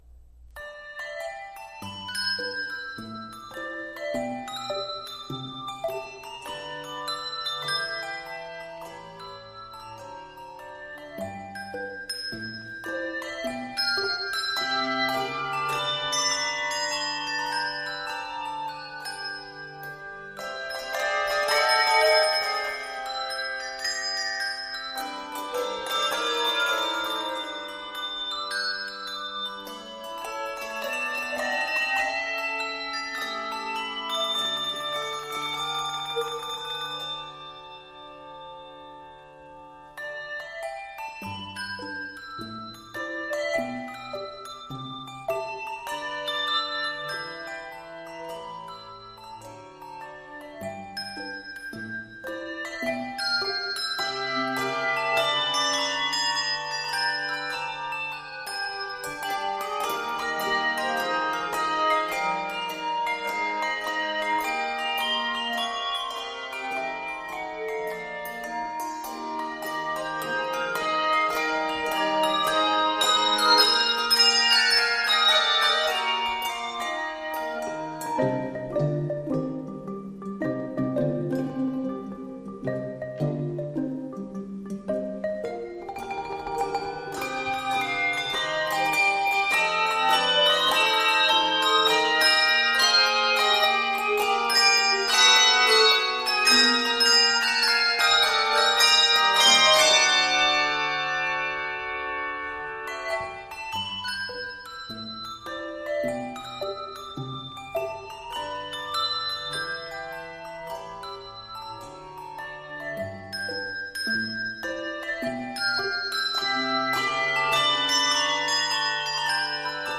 N/A Octaves: 4-6 Level